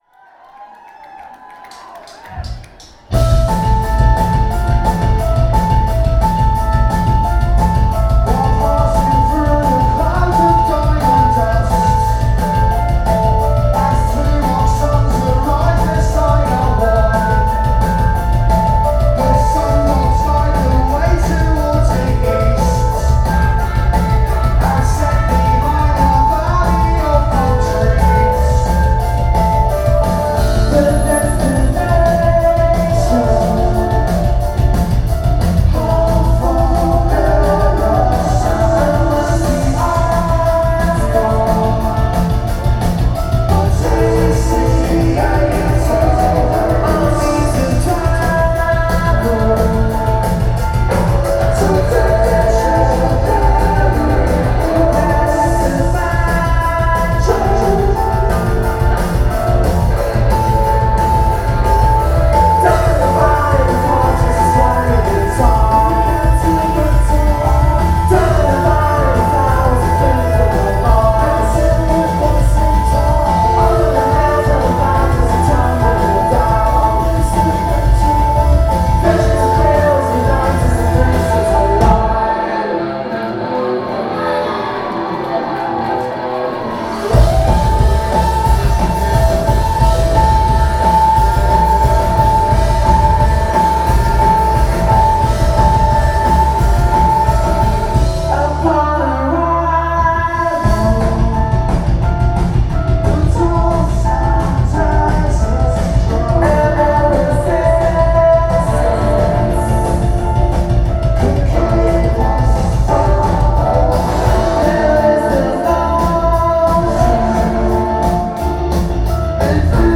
Music Hall of Williamsburg 09/22/10